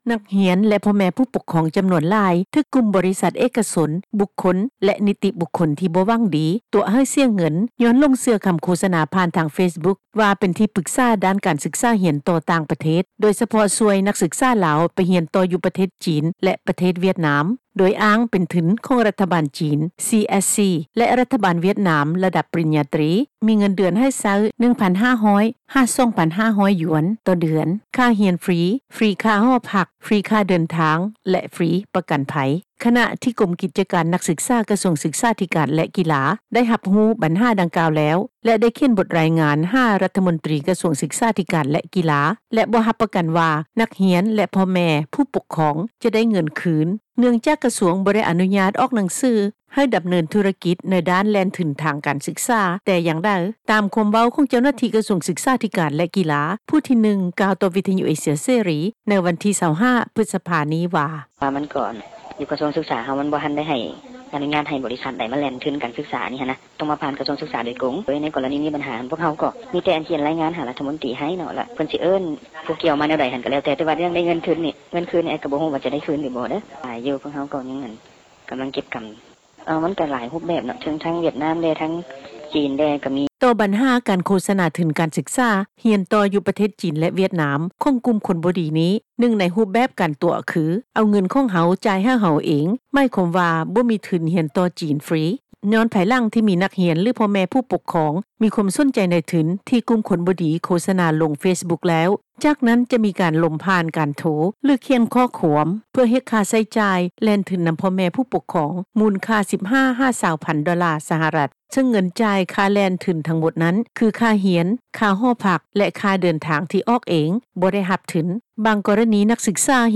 ດັ່ງ ຜູ້ປົກຄອງ ຂອງນັກຮຽນ ກ່າວໃນມື້ດຽວກັນນີ້ວ່າ:
ດັ່ງ ເຈົ້າໜ້າທີ່ ກະຊວງສຶກສາທິການ ແລະ ກິລາ ຜູ້ທີ 2 ກ່າວ ໃນມື້ດຽວກັນນີ້ວ່າ:
ດັ່ງ ເຈົ້າໜ້າທີ່ ກະຊວງສຶກສາທິການ ແລະ ກິລາ ຜູ້ທີ່ 3 ກ່າວໃນມື້ດຽວກັນນີ້ວ່າ: